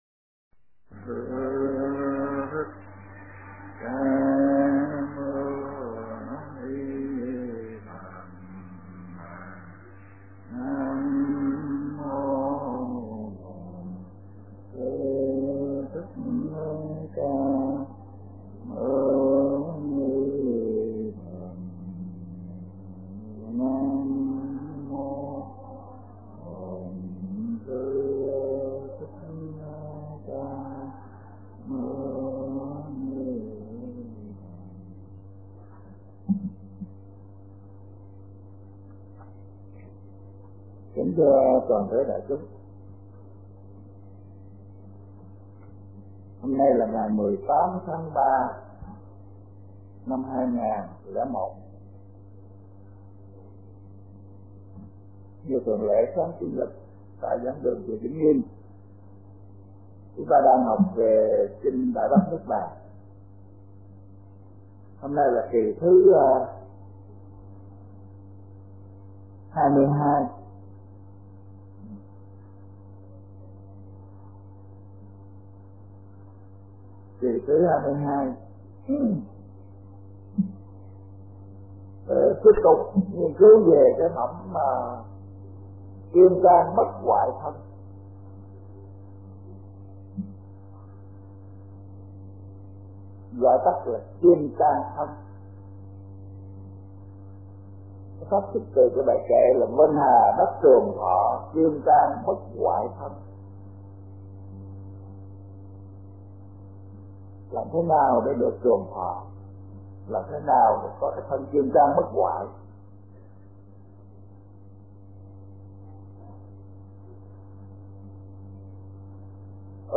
Bài giảng Đại Bát Niết Bàn kinh